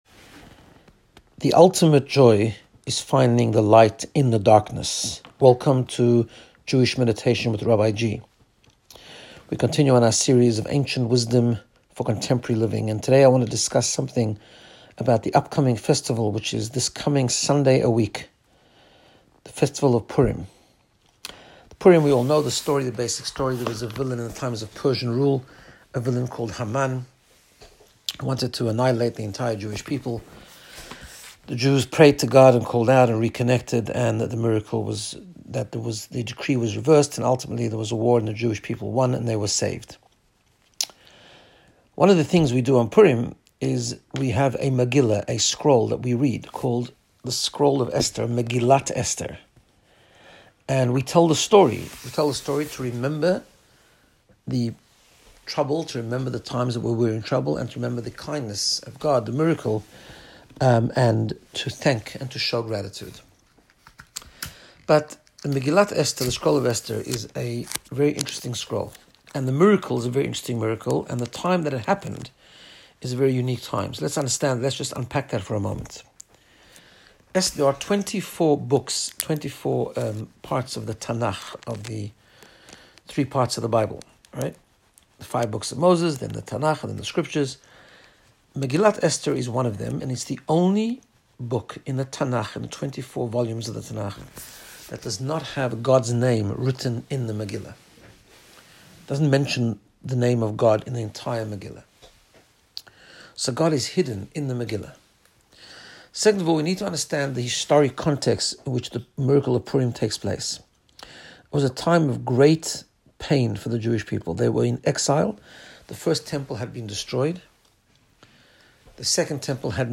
Guided Meditation to get you in the space of Personal Growth and Wellbeing. In this episode we learn how to discover the hidden good when it is not obvious.